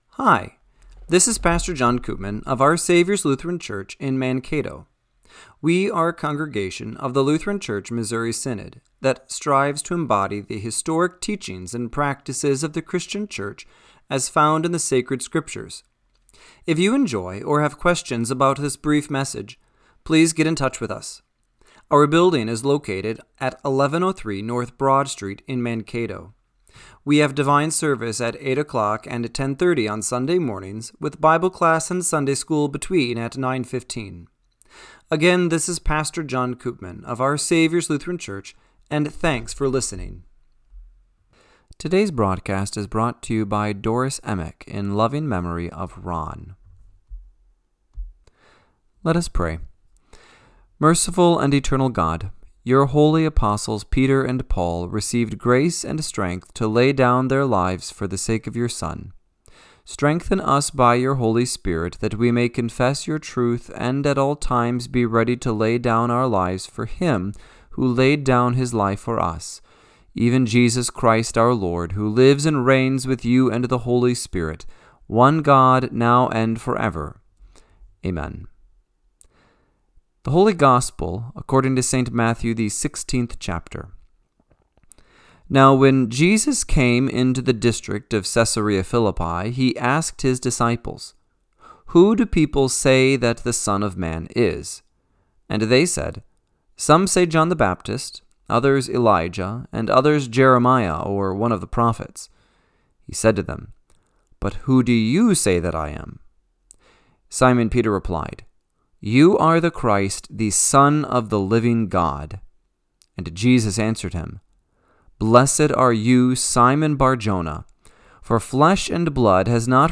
Radio-Matins-6-29-25.mp3